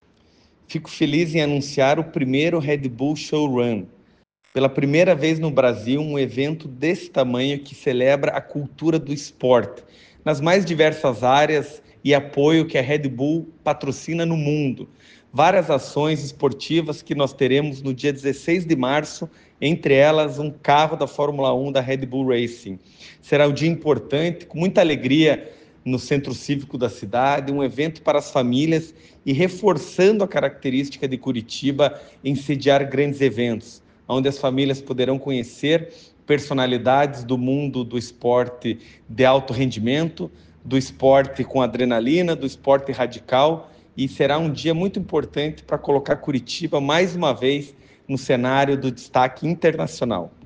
O prefeito Eduardo Pimentel falou sobre a importância para Curitiba da programação do Red Bull Showrun, que já passou Cidade do México, Istambul e Nova Iorque e que é inédita no Brasil.